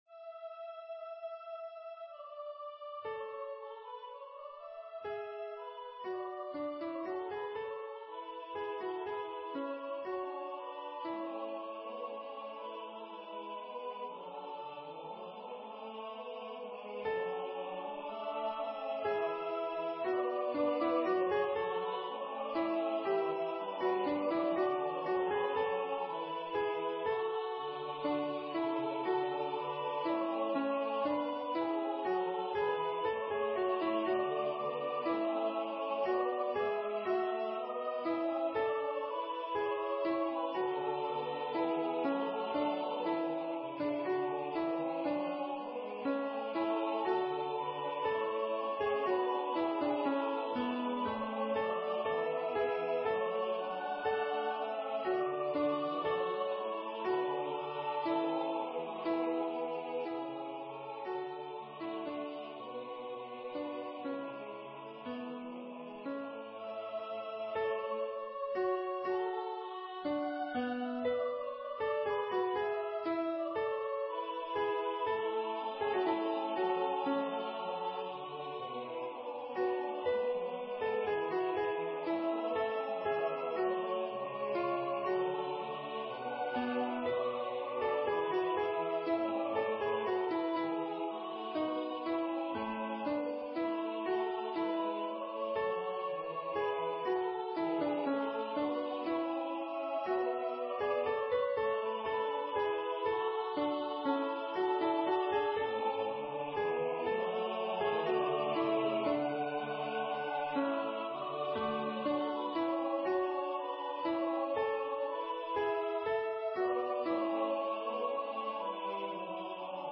(With Accompaniment
Practice Files: Soprano:     Alto:
Number of voices: 4vv   Voicing: SATB
Genre: SacredMotetEucharistic song
EgoSumPanisAltoP.mp3